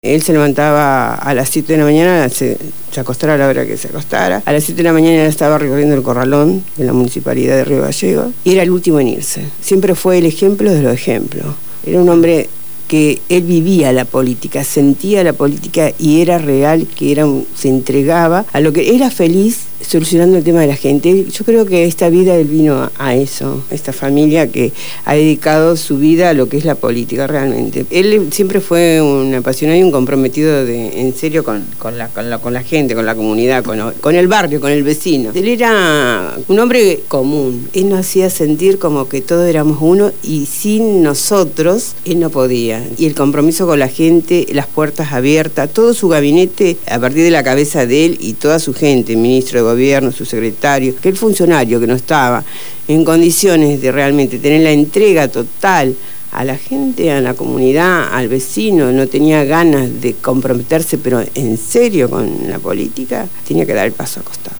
Estuvieron en los estudios de Radio Gráfica FM 89.3 durante el programa «Punto de partida» y nos conducieron por un relato desde las primeras internas que culminan llevándolo a la Intendencia de Río Gallegos, las anécdotas, las dificultades en la función de gobierno, sus sueños y las horas interminables de trabajo desde la visión de dos militantes de la primera hora.